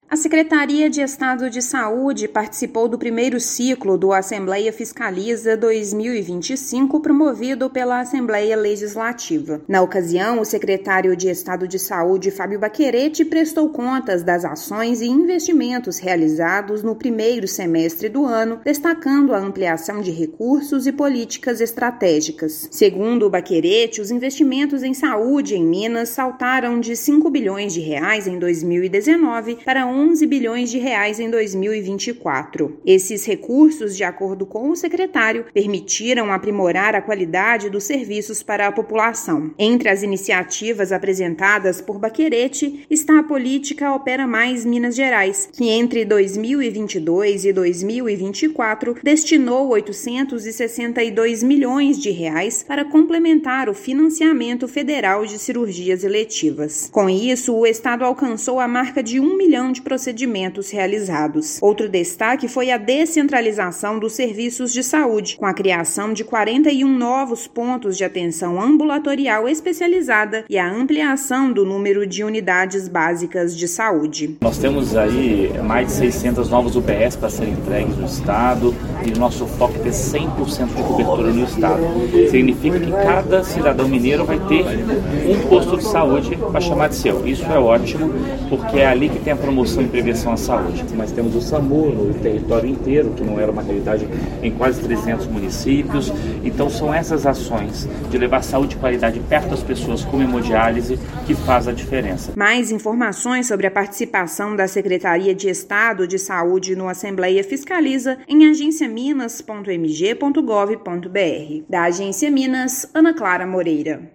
Dirigente da pasta apresentou principais entregas e avanços no setor no primeiro semestre do ano. Ouça matéria de rádio.